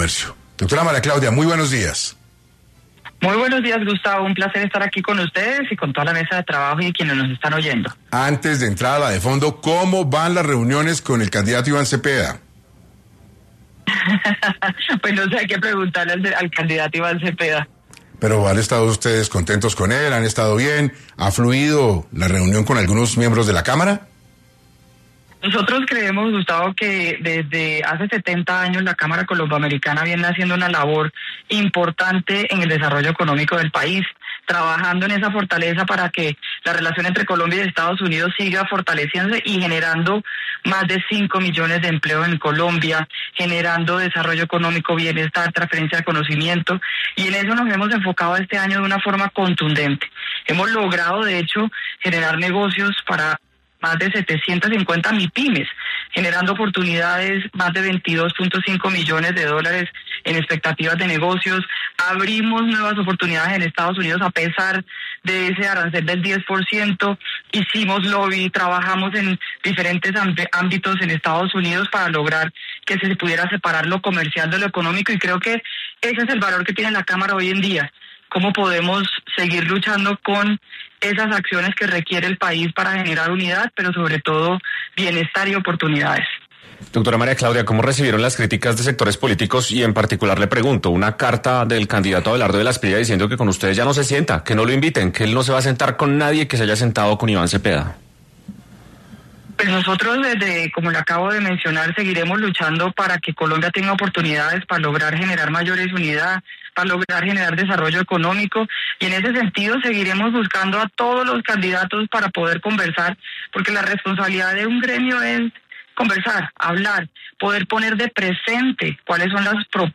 En 6AM de Caracol Radio estuvo María Claudia Lacouture, presidenta de la Cámara Colombo-Americana, quién habló sobre la emergencia económica decretada por el Gobierno